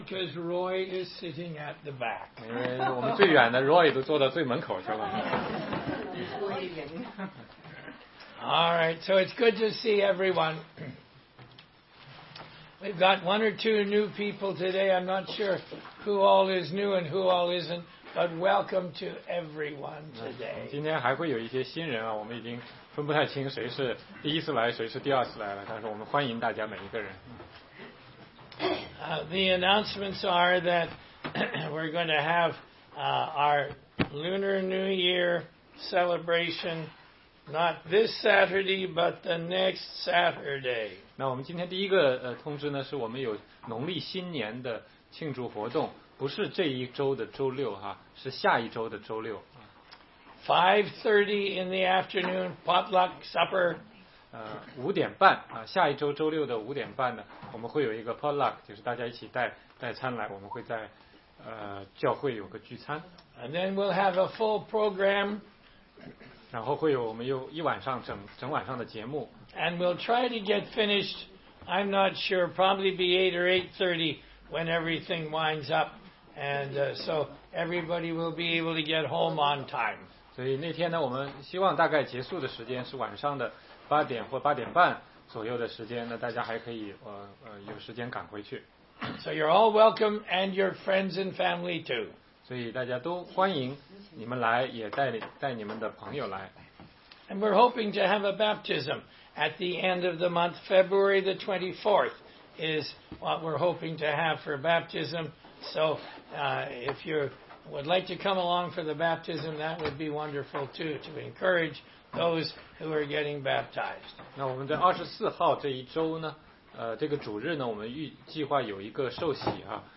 16街讲道录音 - 罗马书2章12节-3章6节